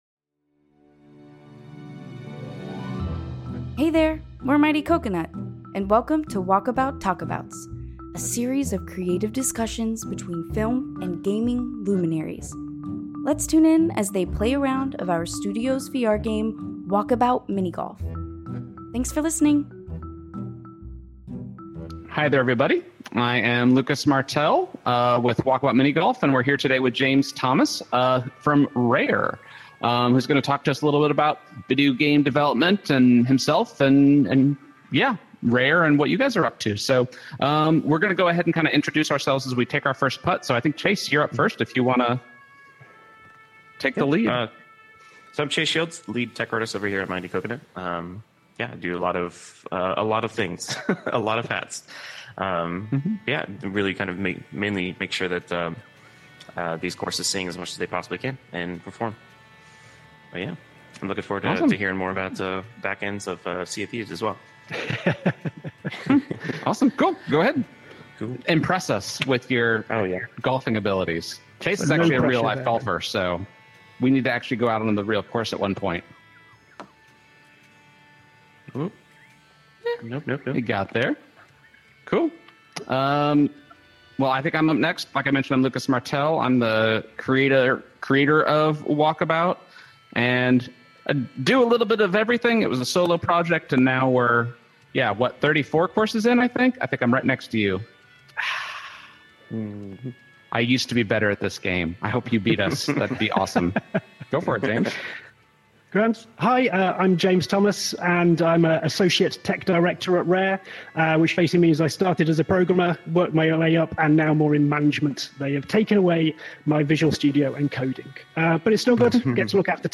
A new series where VR luminaries come together to chat just about whatever's on their mind while playing a few rounds of Walkabout Mini Golf!